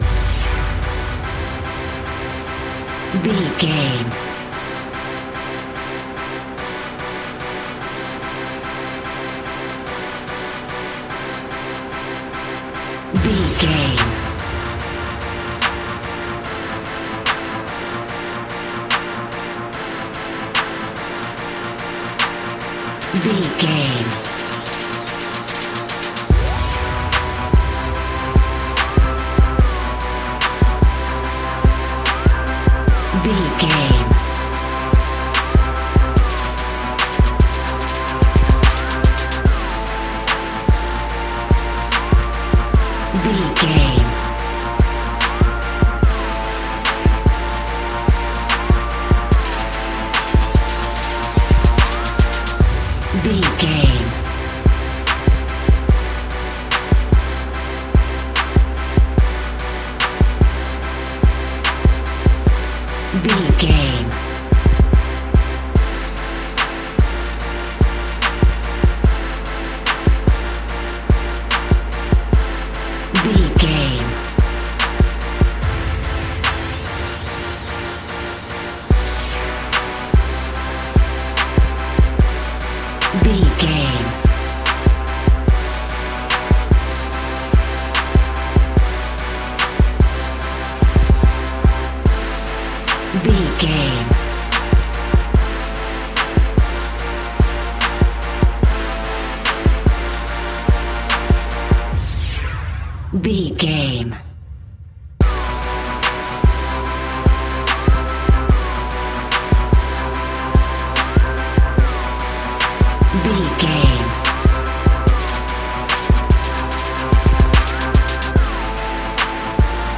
Hip Hop Back in Fashion.
Phrygian
Funk
turntables
synth lead
synth bass
synth drums